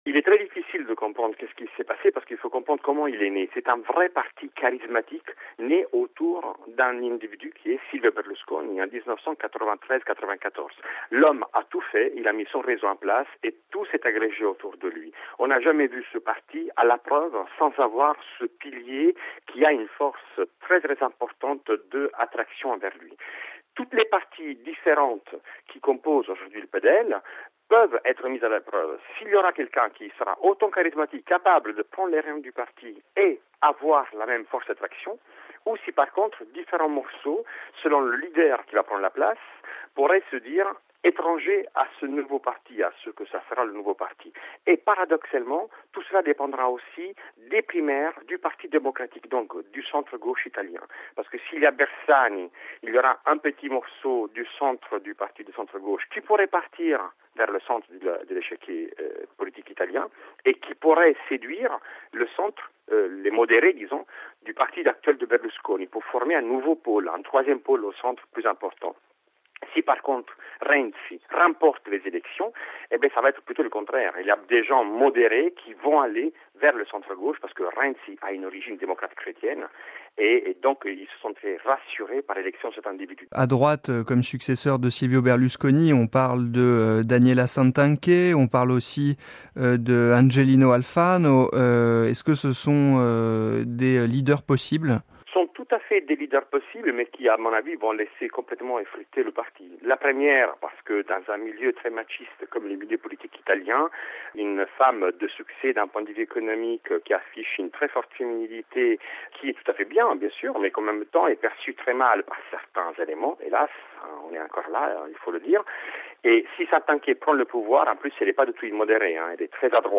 est professeur de géopolitique
Il est interrogé